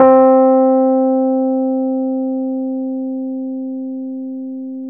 RHODES-C3.wav